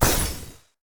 sfx_skill 09_1.wav